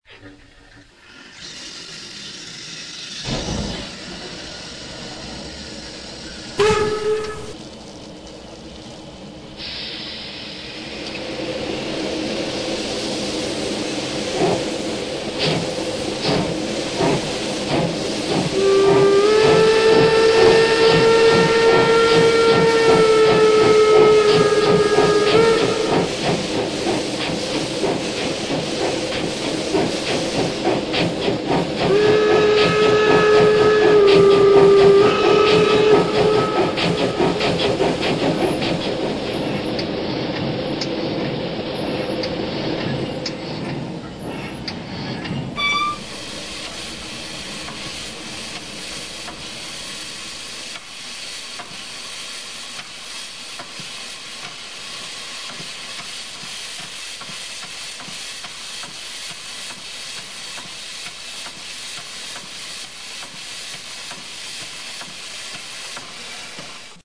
DB Baureihe 044.0 DR Baureihe 44.0 mit Ölfeuerung dampf